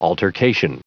Prononciation du mot altercation en anglais (fichier audio)